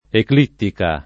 vai all'elenco alfabetico delle voci ingrandisci il carattere 100% rimpicciolisci il carattere stampa invia tramite posta elettronica codividi su Facebook eclittica [ ekl & ttika ] (raro ecclittica ) s. f. (astron.)